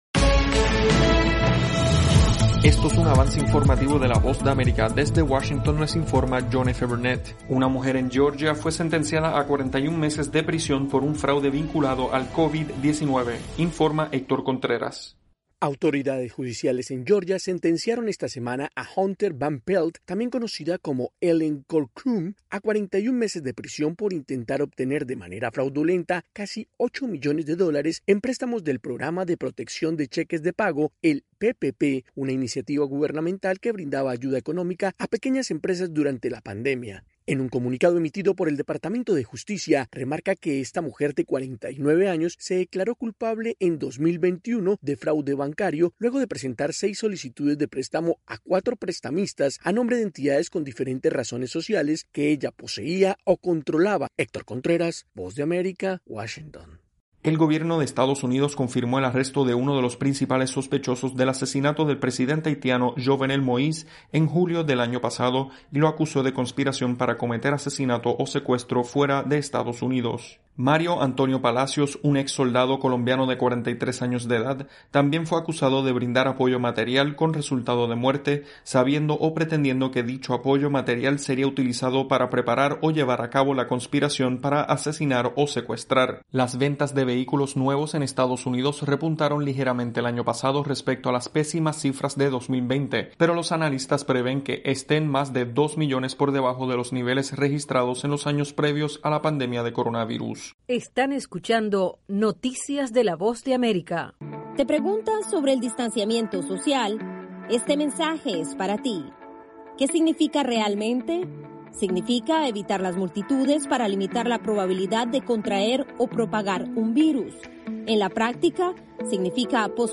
Desde los estudios de la Voz de América en Washington